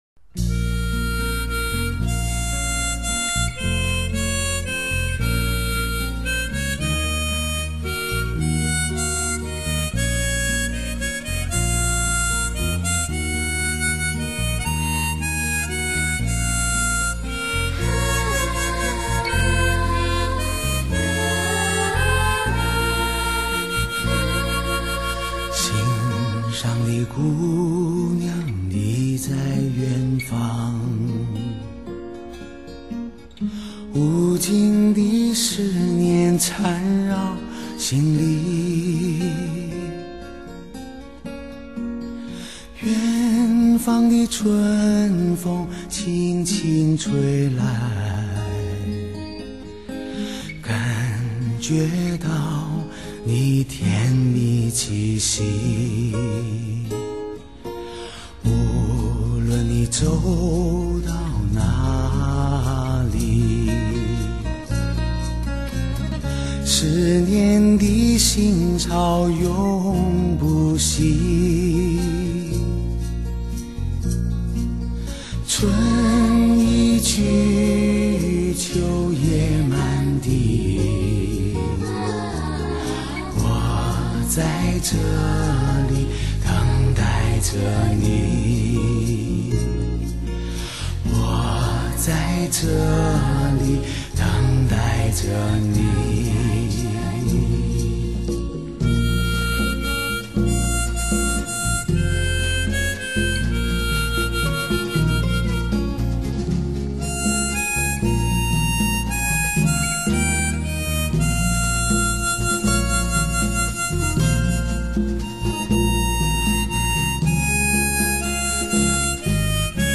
这是一次突破性的尝试，所有音效全部采用
人声现场同时录制，一气呵成的精彩演绎，再加上